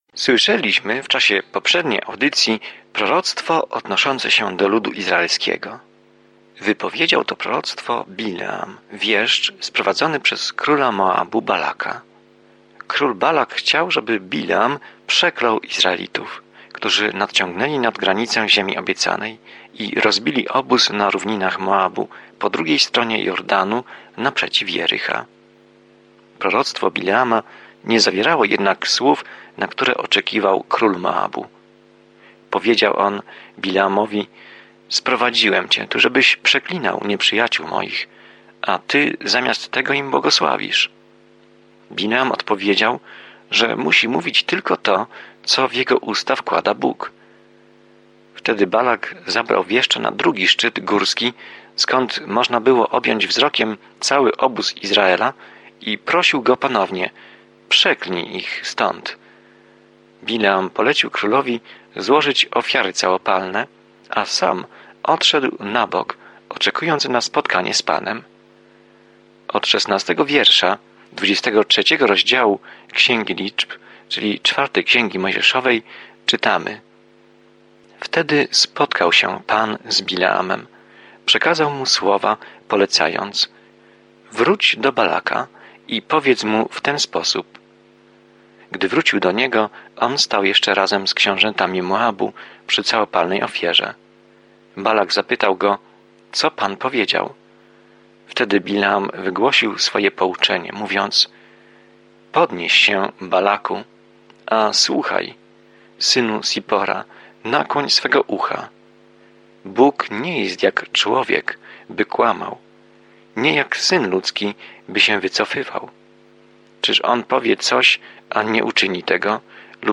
Pismo Święte Liczb 23:14-30 Liczb 24:1-25 Dzień 17 Rozpocznij ten plan Dzień 19 O tym planie W Księdze Liczb spacerujemy, wędrujemy i oddajemy cześć Izraelowi przez 40 lat na pustyni. Codziennie podróżuj po Liczbach, słuchając studium audio i czytając wybrane wersety słowa Bożego.